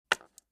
StoneSound7.mp3